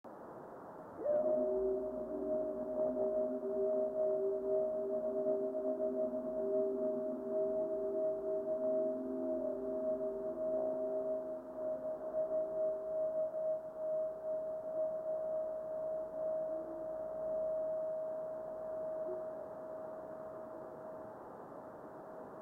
Good reflection.